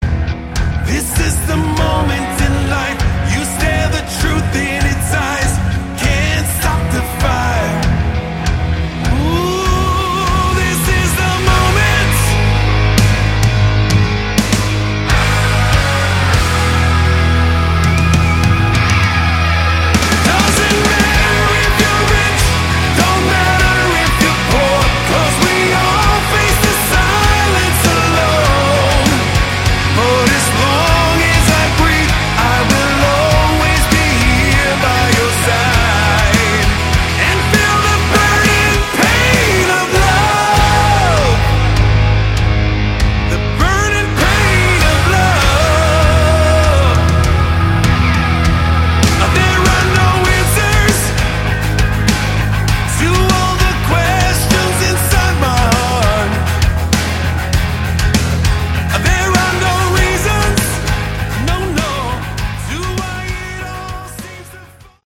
Category: Hard Rock
guitar, bass, keyboards, vocals